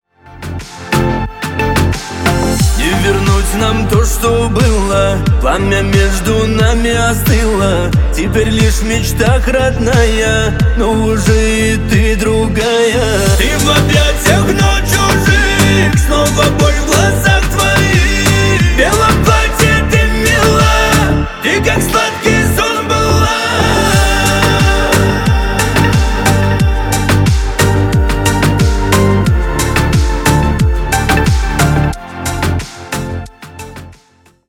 Поп Музыка
кавказские